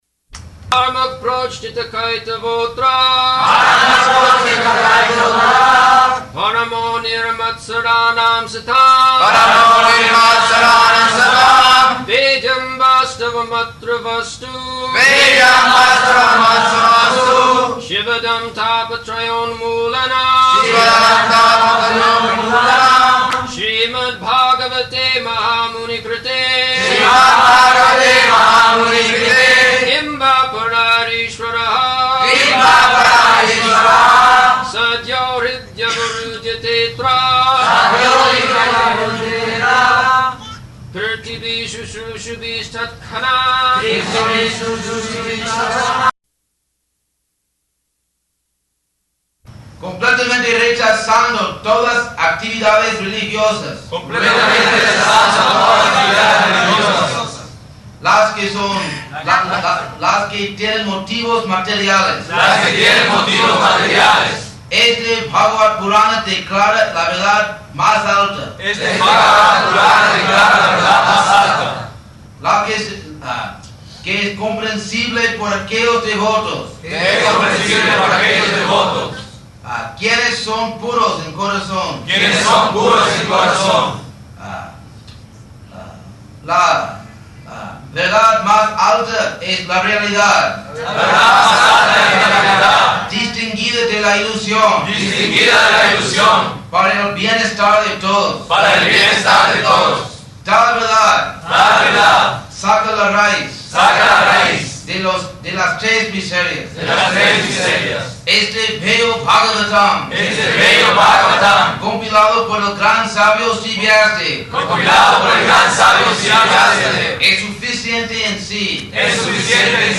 Location: Caracas